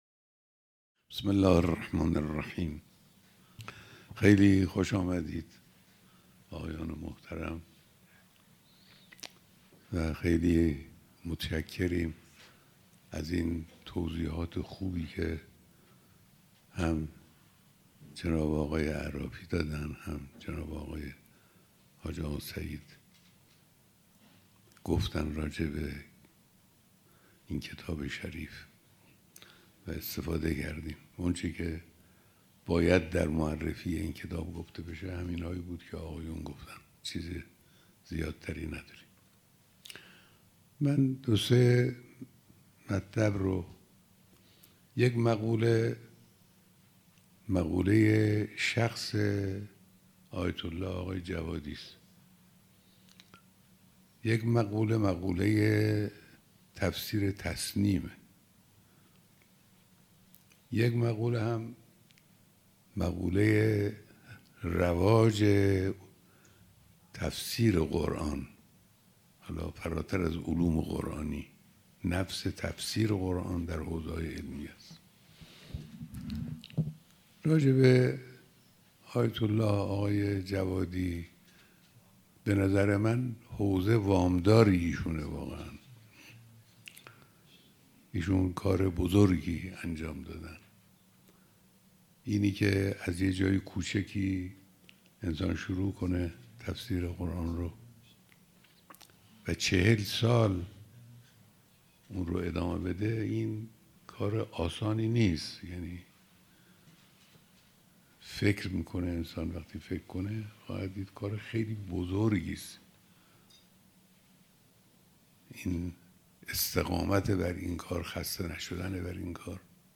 بیانات در دیدار دست‌اندرکاران برگزاری همایش بین‌المللی تفسیر تسنیم